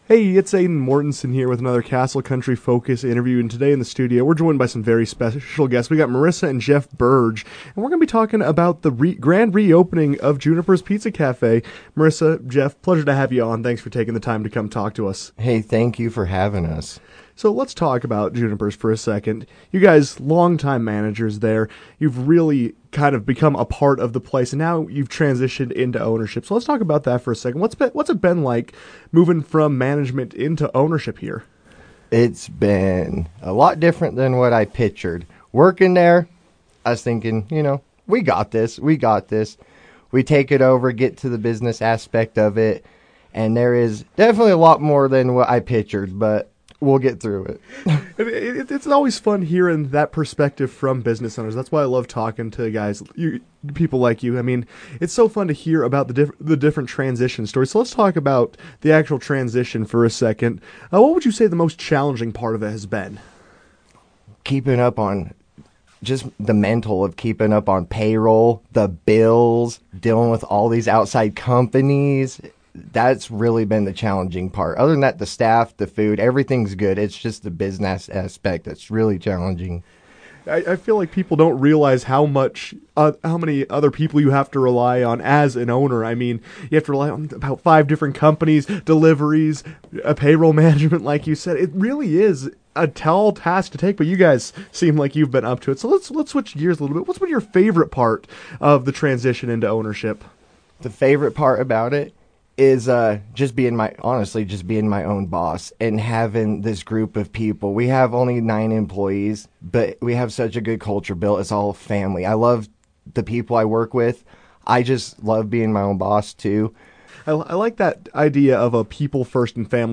joined the KOAL newsroom to discuss the transition from employee to owner and what customers can expect moving forward.